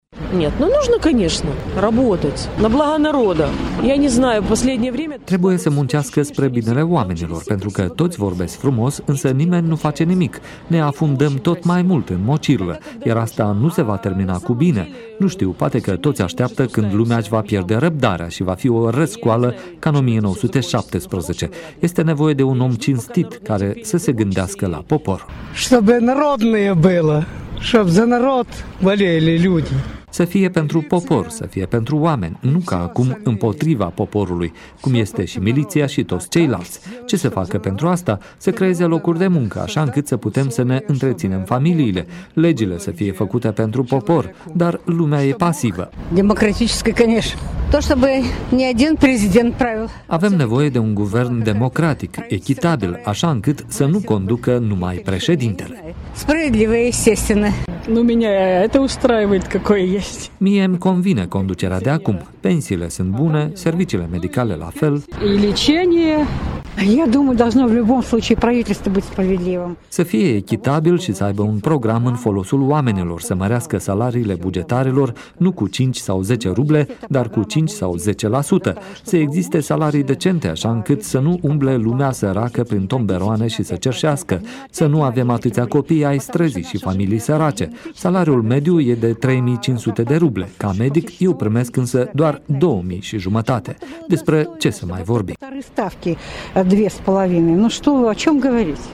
Sondaj de opinie la Tiraspol şi Bender